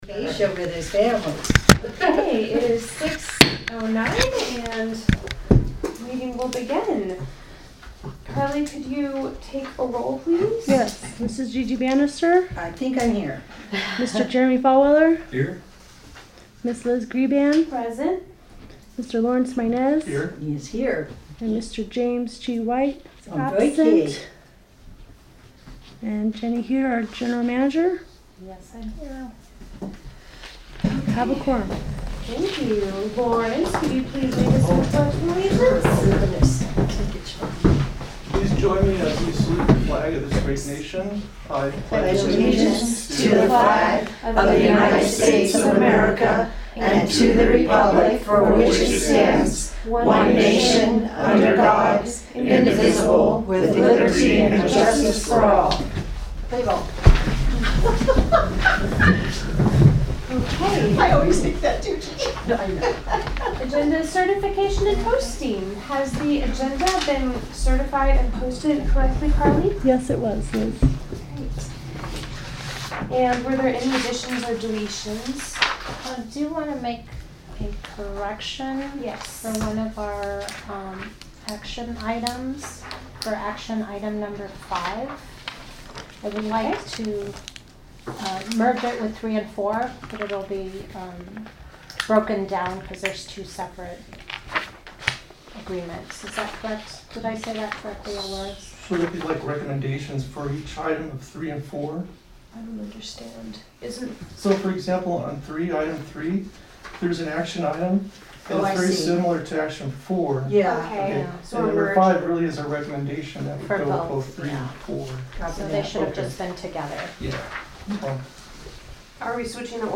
Meeting for Monday, July 22, 204 will be held at the Park District Office, 26577 State Hwy 18, Rimforest, CA 92378 and will begin at 6:00 PM.